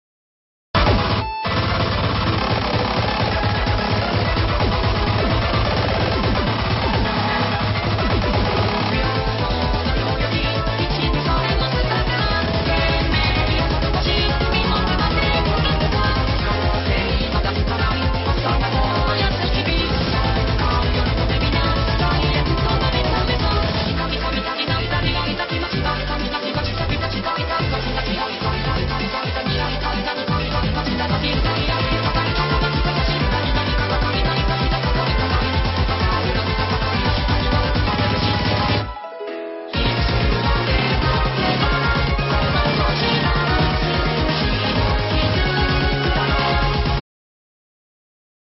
涉及术力口本家和泛术力口歌姬